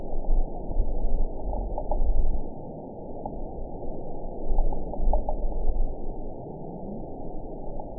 event 916112 date 12/25/22 time 08:25:50 GMT (3 years ago) score 9.30 location TSS-AB03 detected by nrw target species NRW annotations +NRW Spectrogram: Frequency (kHz) vs. Time (s) audio not available .wav